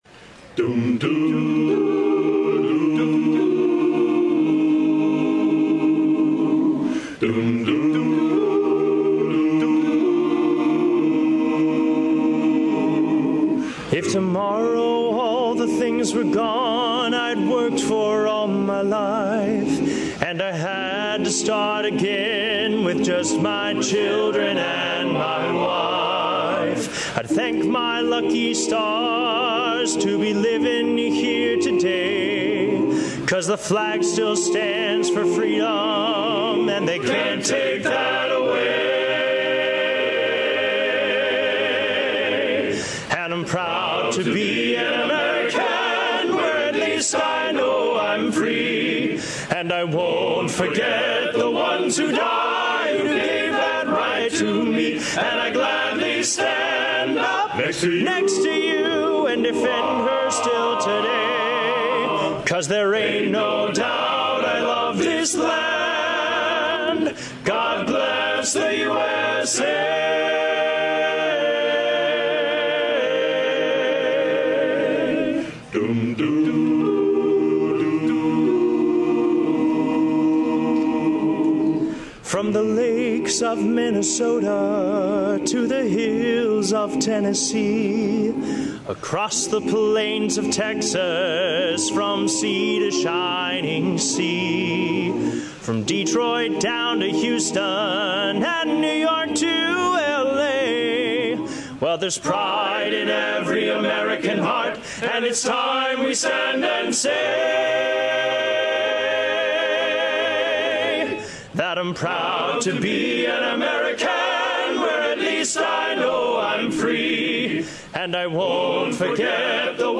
Seventy-four candidates received their United States citizenship at the event. David N. Saperstein, former U.S. ambassador-at-large for international religious freedom and prominent Reform rabbi, addressed the crowd. The event took place on the back portico of Thomas Jefferson’s home, Monticello, in Charlottesville, Virginia.